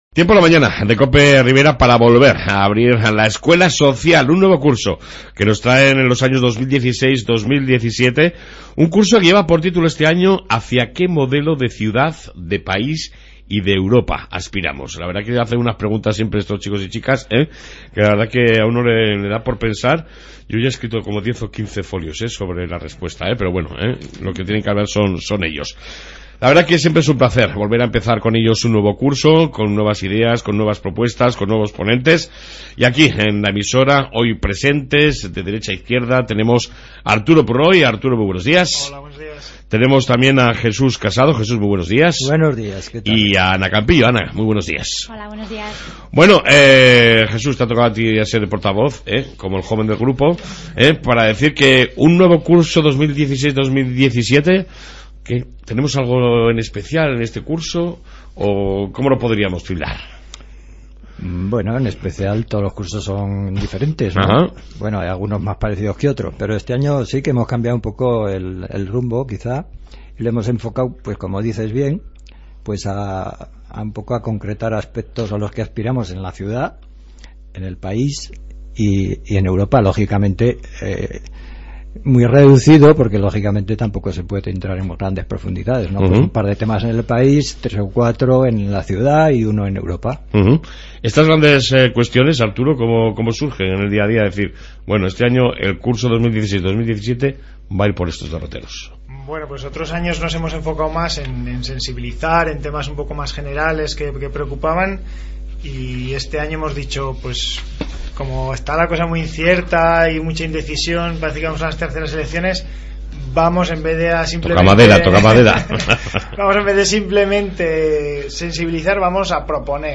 Comenzamos un nuevo curso de la Escuela Social de Tudela y la Ribera, que este año se desarrollará bajo el titulo ¿Hacia qué modelo de ciudad, de país, y de Europa aspiramos?Aqui la entrevista.